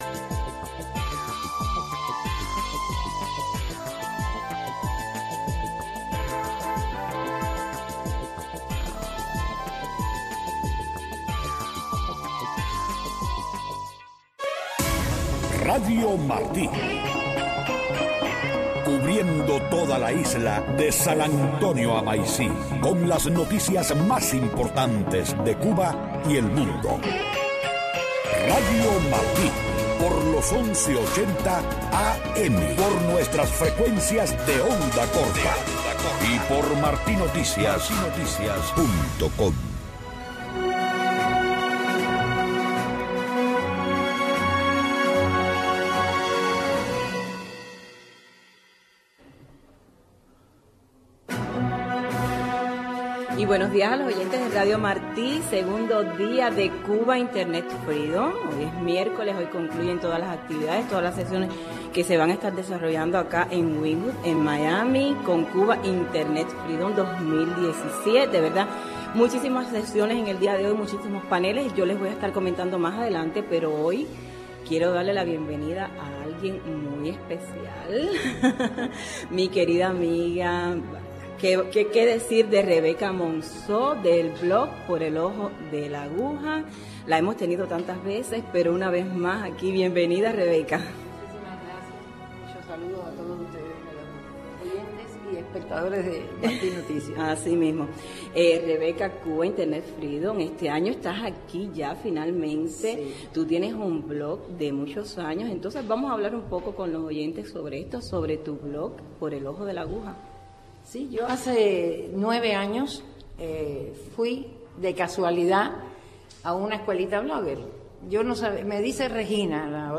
Participa y comenta en éste franco diálogo sobre una diversidad de temas dirigidos a la mujer cubana.